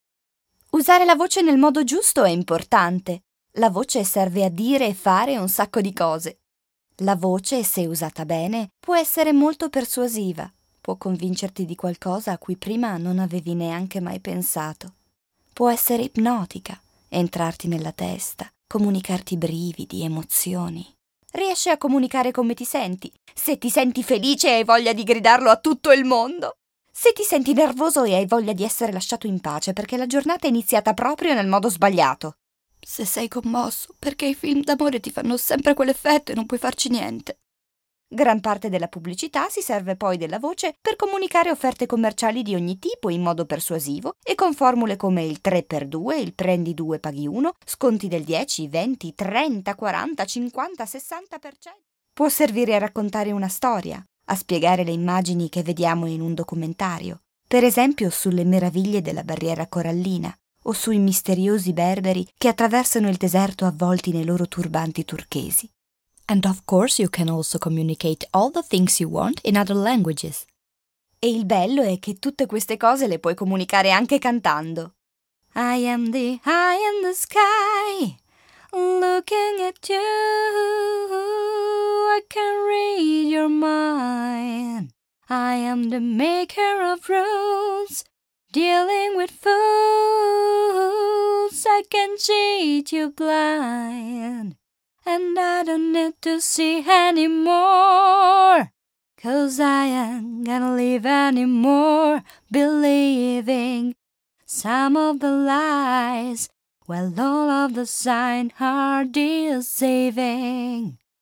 Kein Dialekt
Sprechprobe: Sonstiges (Muttersprache):
I am a speaker and dubber from Milan with a fresh, professional, extremely versatile kind of voice and, of course, perfect diction.